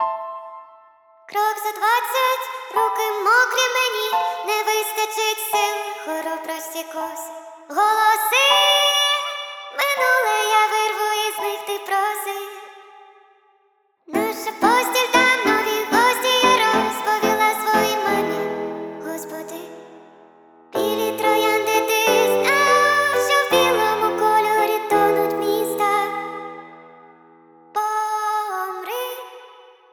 Жанр: Поп / Инди / Русские
# Indie Pop